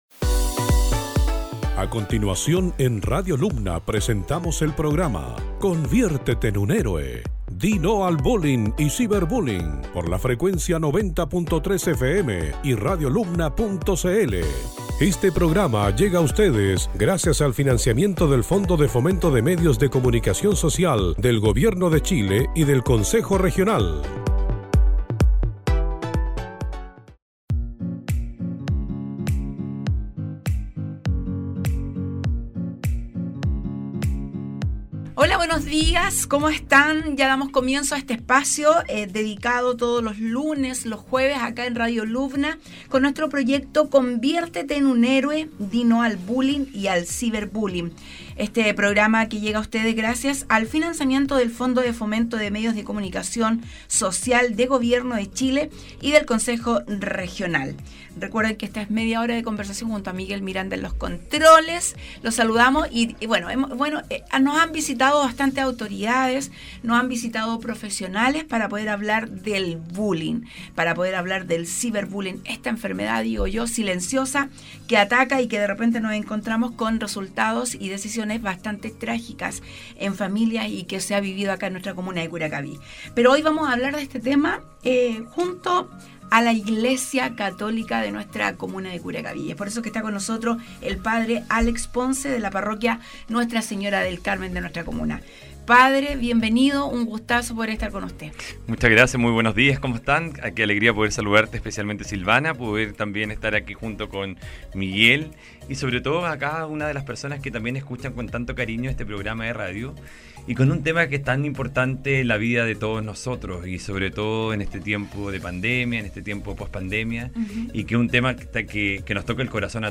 Durante este capitulo tuvimos la oportunidad de entrevistar